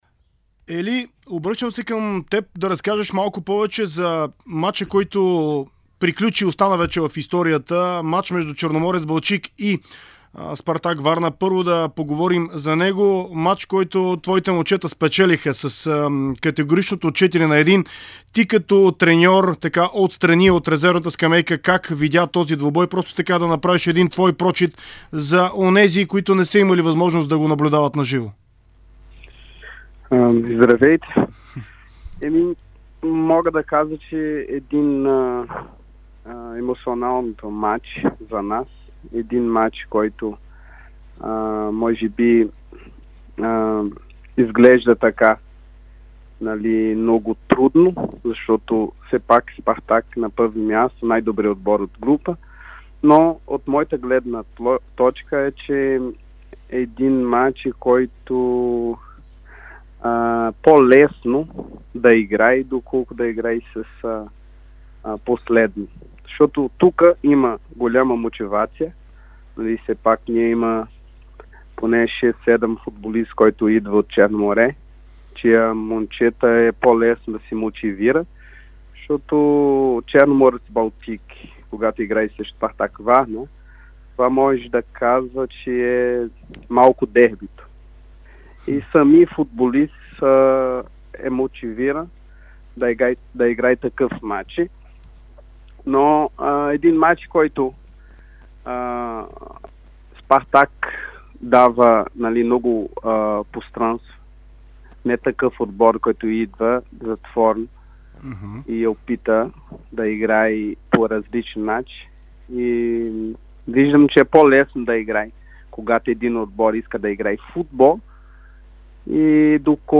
интервю